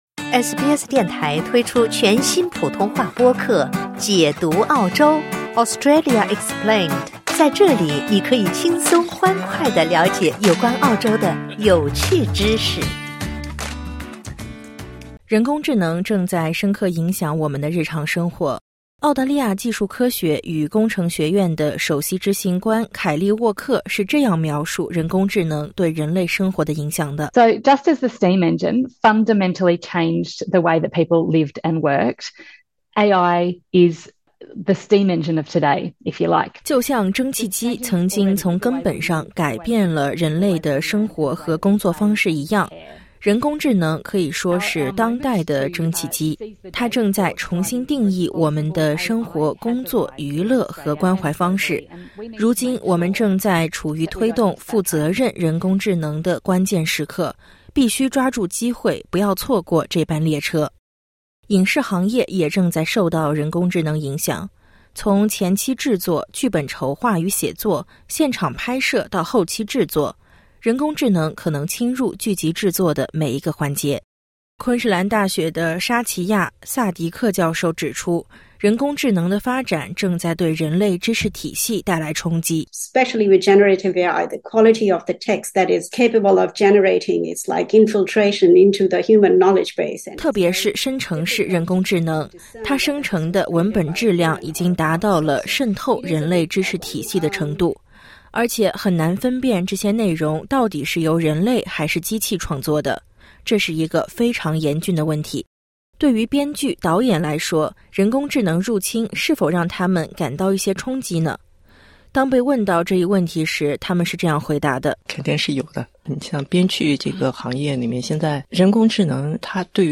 点击音频，收听综合报道。